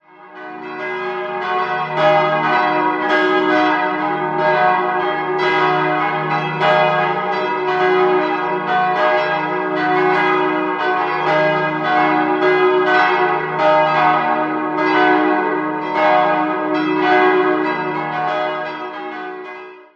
Glocke 1 dis'+0 1.250 kg 1.275 mm 1991 Karlsruher Glocken- und Kunstgießerei Glocke 2 fis'-1 703 kg 1.050 mm 1951 Karl Czudnochowsky, Erding (Bronze) Glocke 3 gis'-2 500 kg 958 mm 1924 Heinrich Ulrich, Apolda/Kempten Glocke 4 h'-3 280 kg 772 mm 1951 Karl Czudnochowsky, Erding (Bronze) Quelle: Amt für Kirchenmusik, Eichstätt